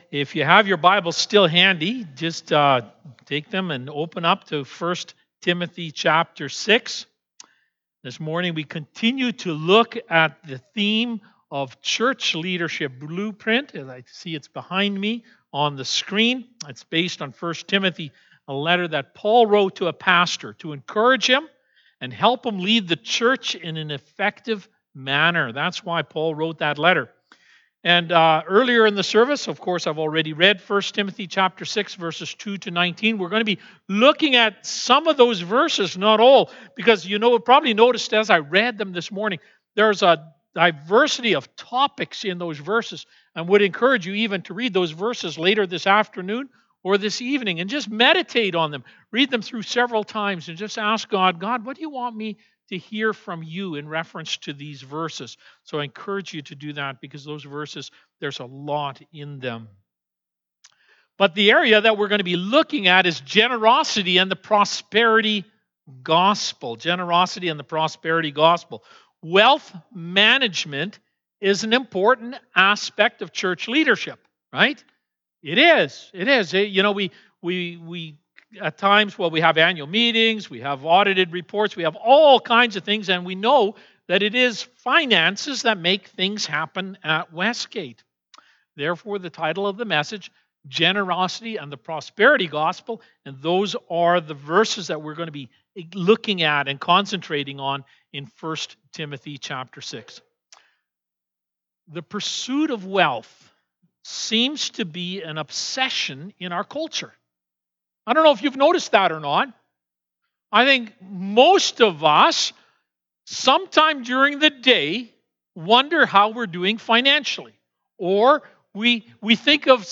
Sermons | Westgate Alliance Church